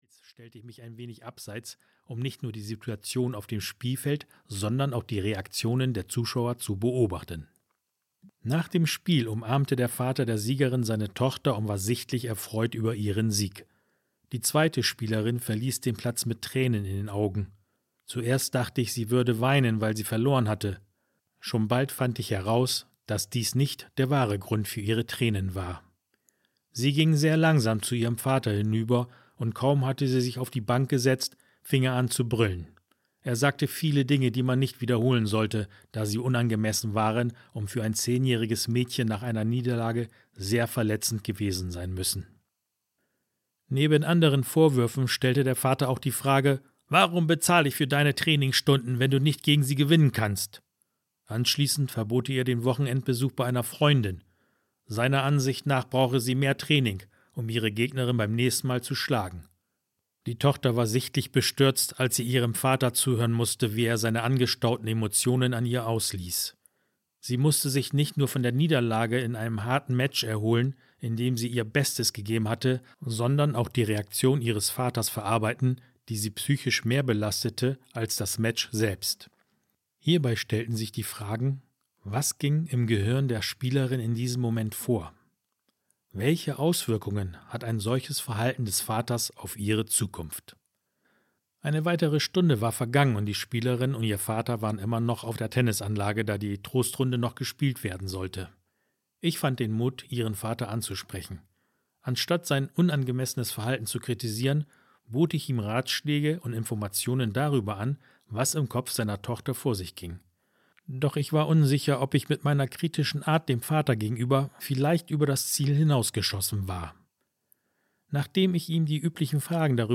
Angstlos audiokniha
Ukázka z knihy